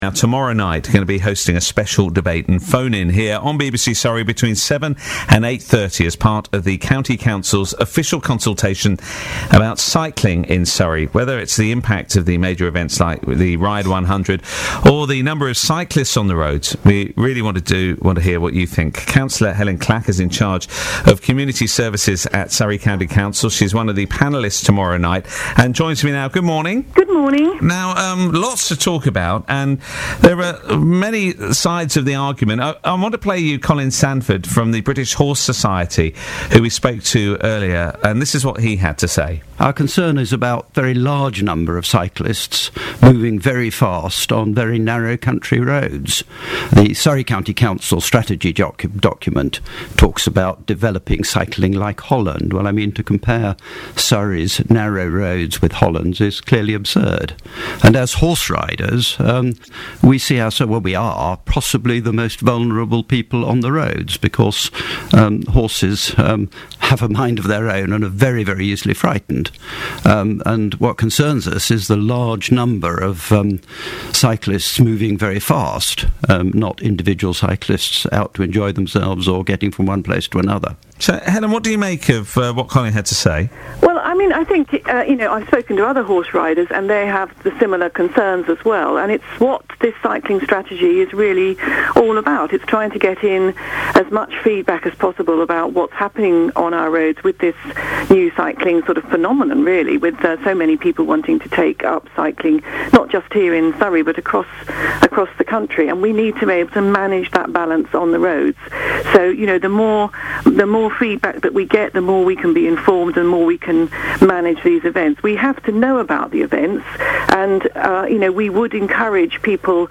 BBC interview Helyn Clack on Surrey’s cycling strategy